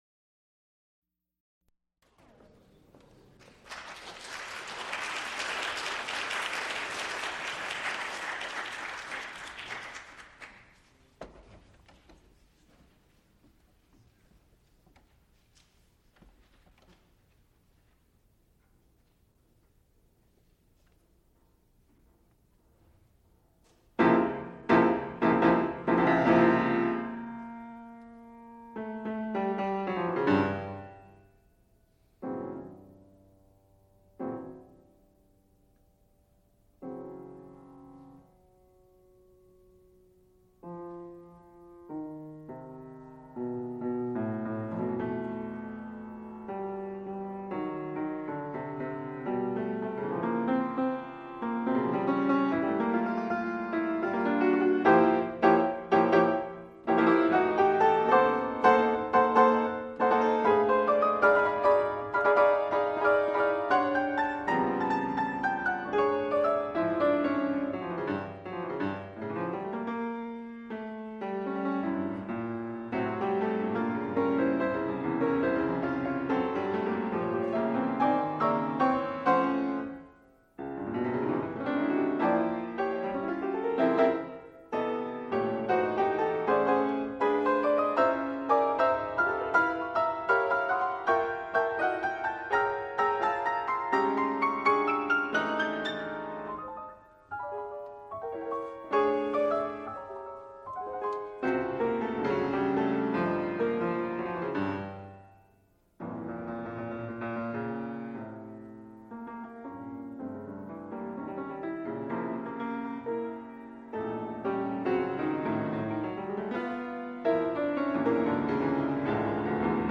piano
cello
Extent 4 audiotape reels : analog, half track, stereo, 7 1/2 ips ; 7 in.
musical performances
Piano music Cello and piano music